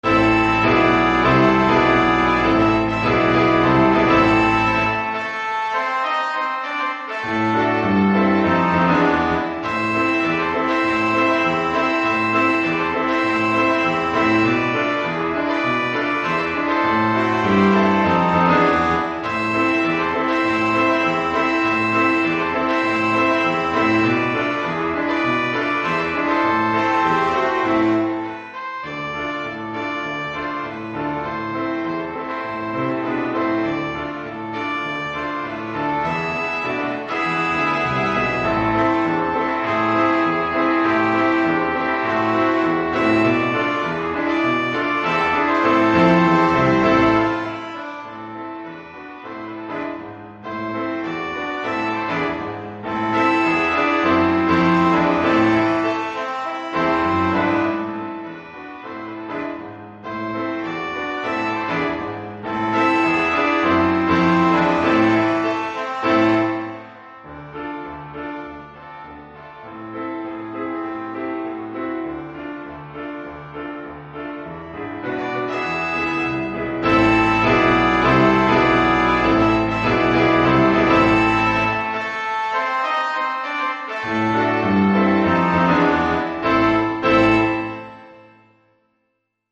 Gattung: 5-Part Ensemble
Besetzung: Ensemble gemischt
Keyboard, Drums & Percussions optional.